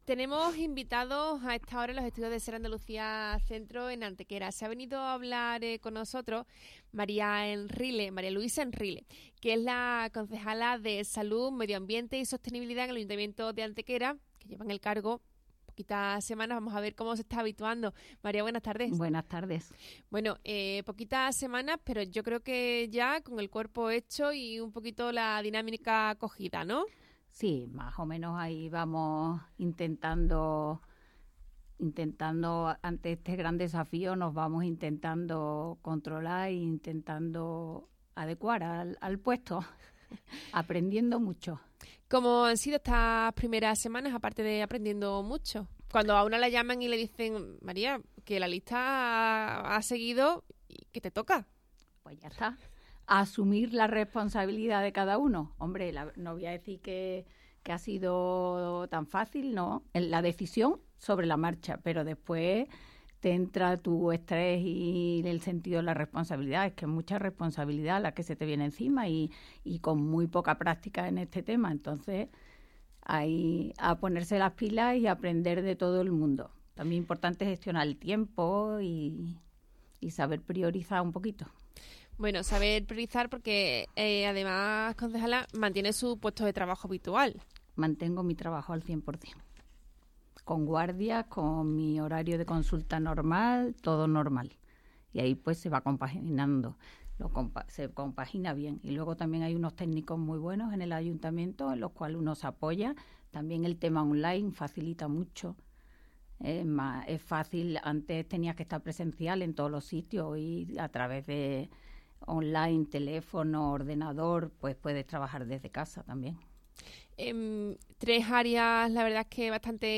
Entrevista María Luisa Enrile. Concejala Salud, Medio Ambiente y Sostenibilidad.